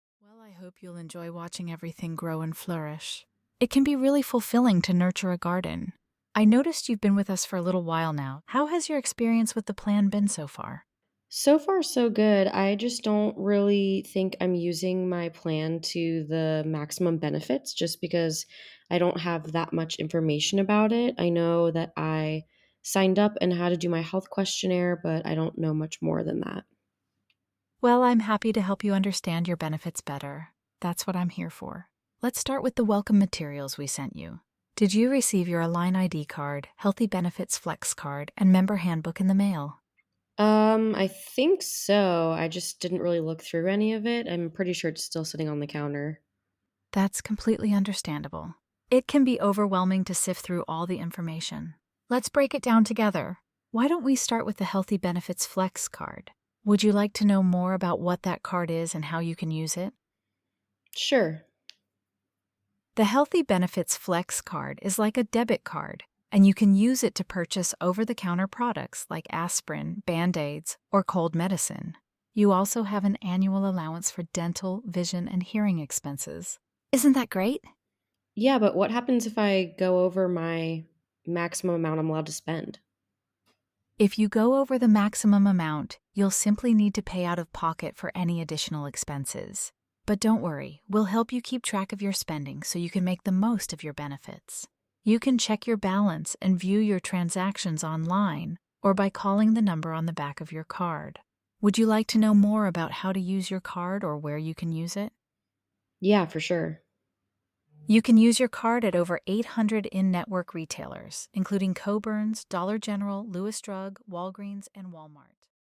Introducing Your New AI Health Navigator, Jane!
You may get a call from our AI Health Navigator, Jane, who will welcome you to the plan and assist in assuring you have everything you need to use your benefits.
sanford-welcome-demo_v04